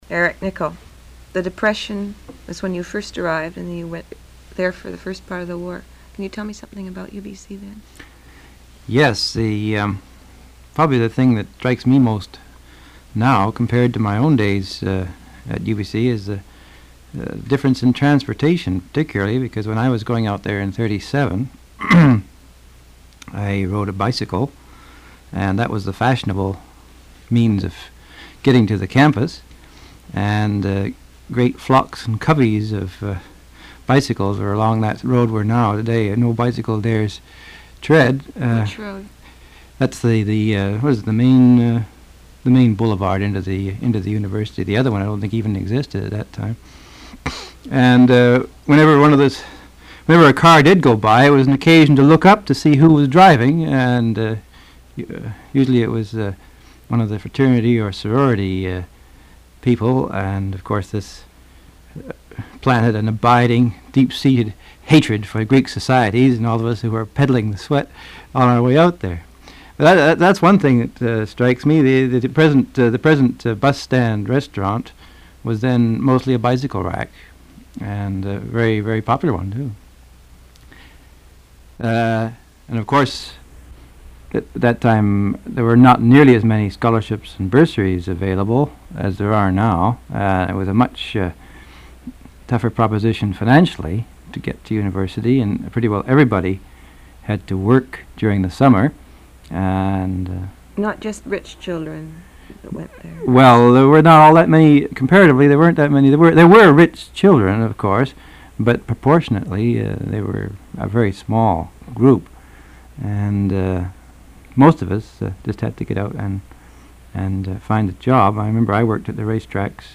[Interview with Eric Nicol - UBC fiftieth anniversary]